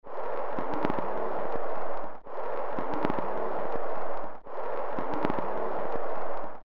Another interesting anomaly happened in this recording as well. It resembles a
gd6-hoot.mp3